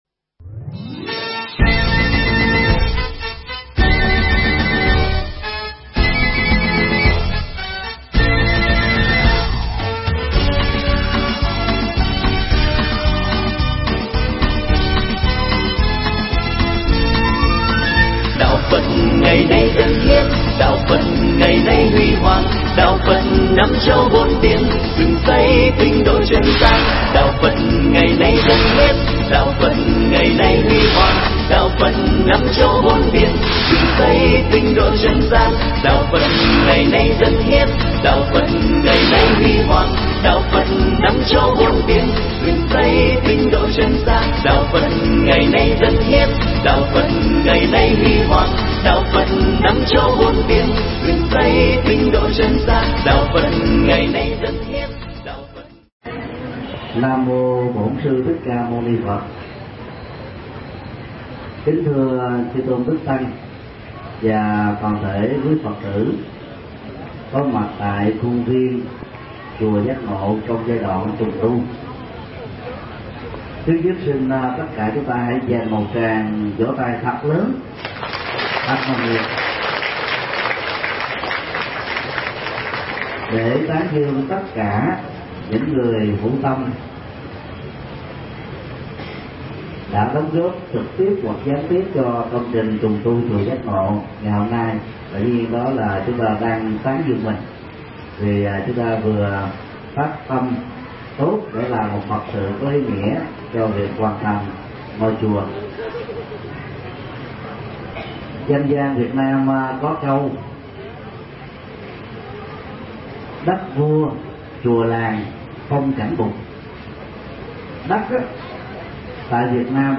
Pháp thoại
tại chùa Giác Ngộ